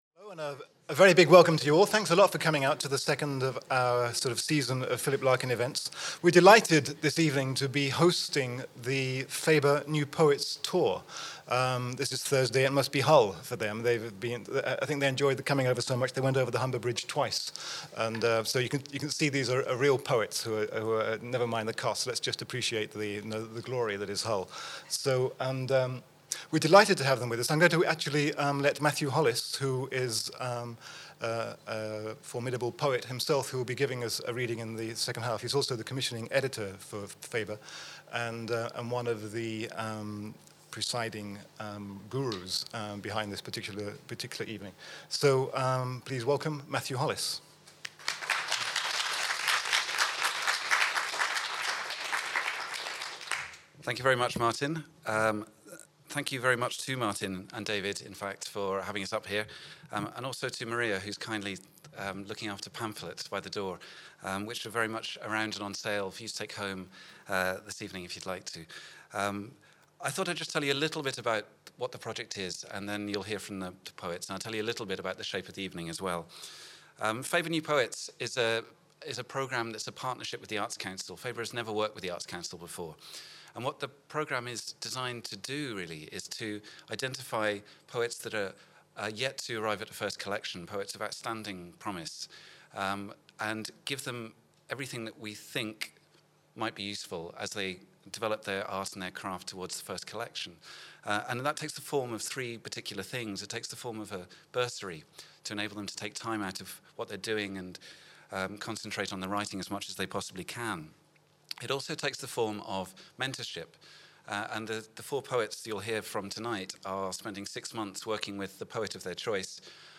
The Faber New Poets - Four young-blood poets read from their first published works, winners in top poetry publisher Faber's drive to find and promote the most vibrant new poetic talent.